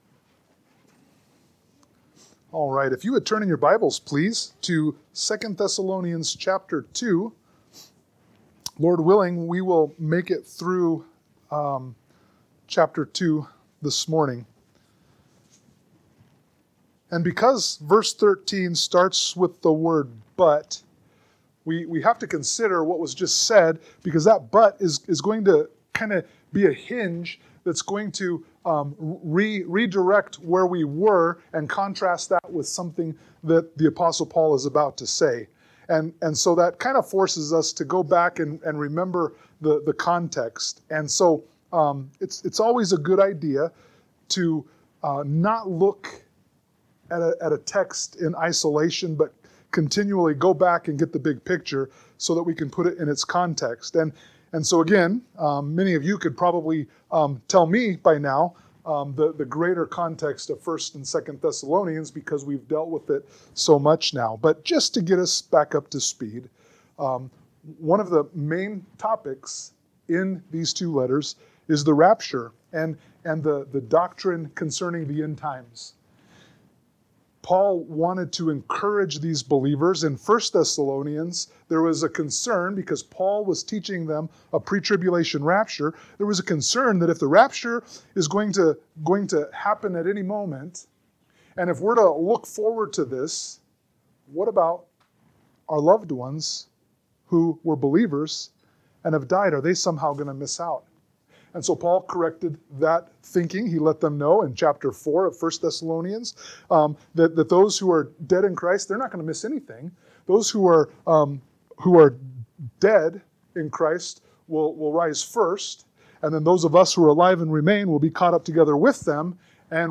sermon-6_22_25.mp3